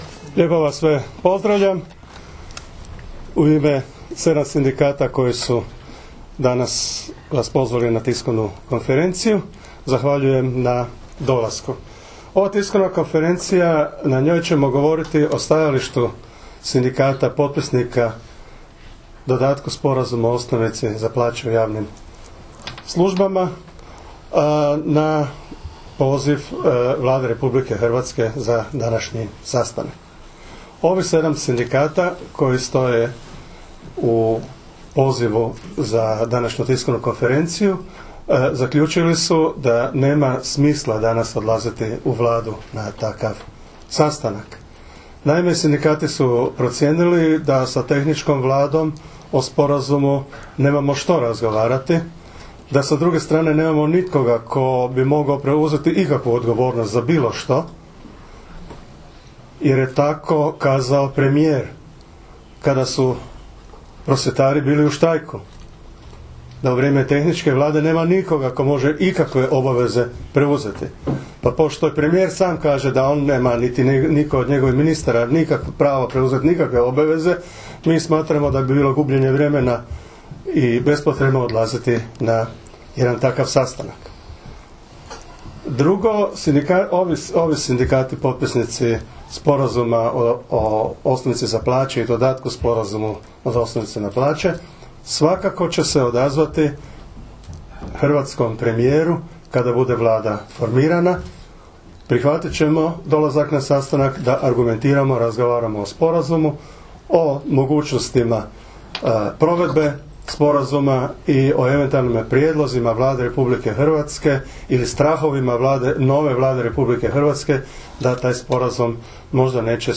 Čelnici sedam sindikata javnih službi: Sindikata hrvatskih učitelja, Nezavisnog sindikata zaposlenih u srednjim školama Hrvatske, Nezavisnog sindikata znanosti i visokog obrazovanja, Sindikata zaposlenika u djelatnosti socijalne skrbi Hrvatske, Hrvatskog liječničkog sindikata, Hrvatskog strukovnog sindikata medicinskih sestara – medicinskih tehničara i Hrvatskog sindikata djelatnika u kulturi, 8. prosinca 2015. održali su konferenciju za medije povodom poziva na sastanak koji im je upućen iz Ministarstva rada i mirovinskog sustava radi dogovora o mogućim daljnjim aktivnostima vezanim za početa